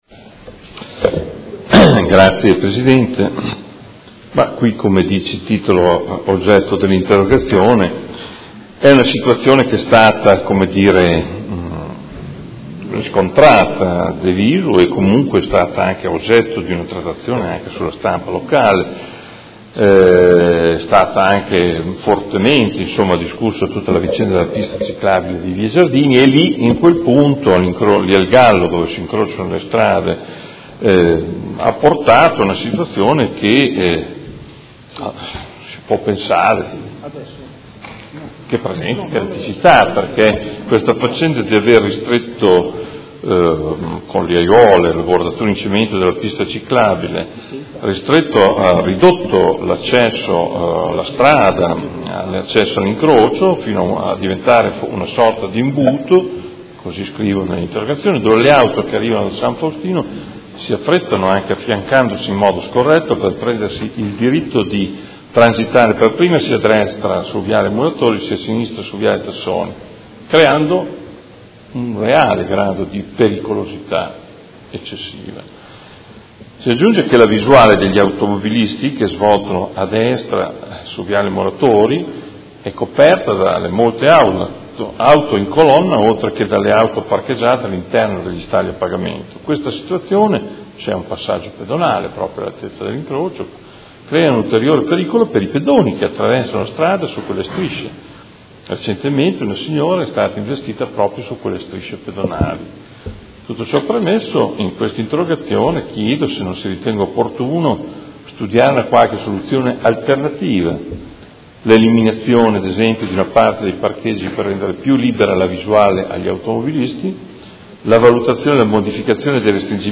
Seduta del 15/06/2017. Interrogazione del Consigliere Morandi (FI) avente per oggetto: La circolazione delle auto, la ciclabile e i passaggi pedonali di Via Giardini “Al Gallo” presentano cirticità.